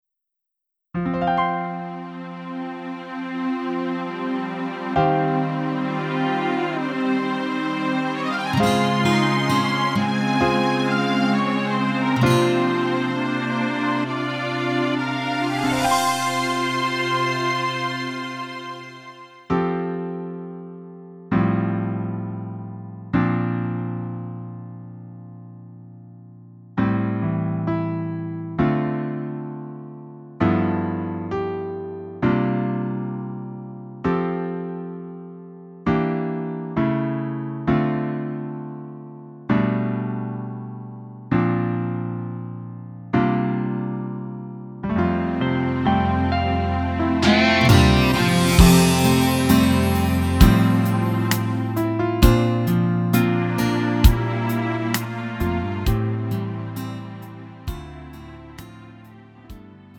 음정 원키 4:13
장르 구분 Lite MR